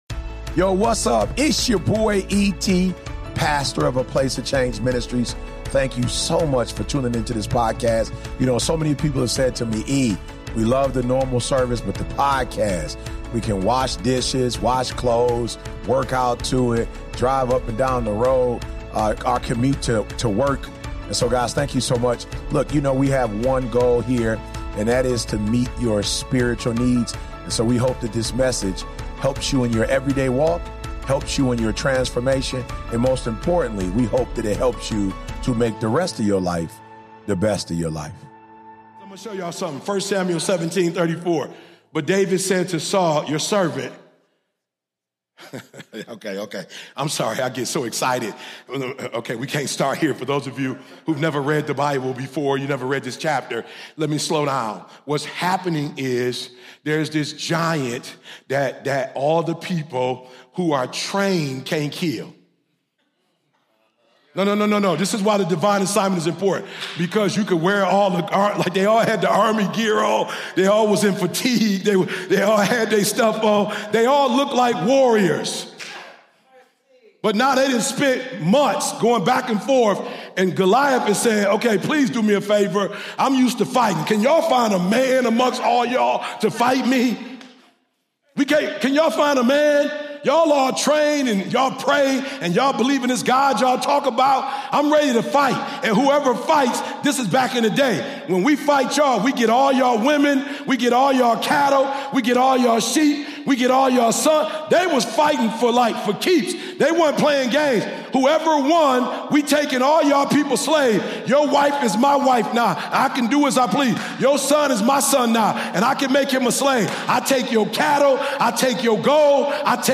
In this week's powerful message from APOC Ministries, Dr. Eric Thomas delivers a straight-up challenge: stop blaming the devil for what your laziness, procrastination, and lack of execution are causing.